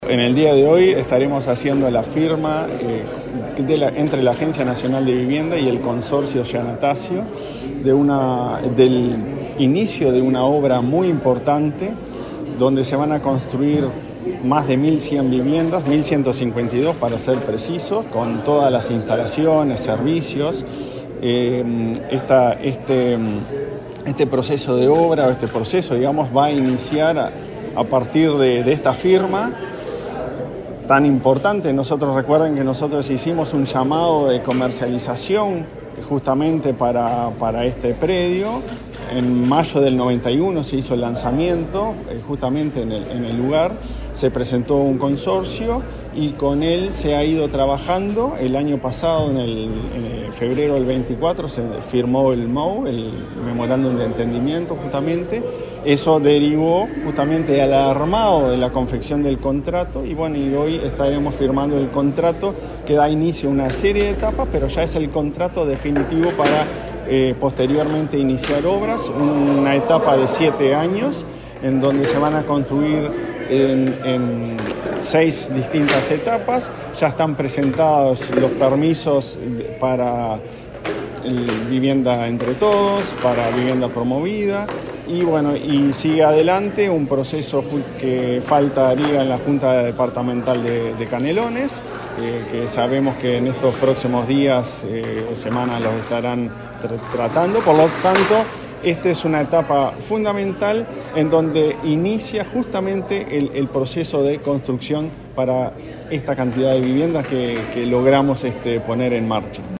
Declaraciones del presidente de la ANV, Klaus Mill
El presidente de la Agencia Nacional de Vivienda (ANV), Klaus Mill, dialogó con la prensa, luego de participar en el sorteo de unidades del edificio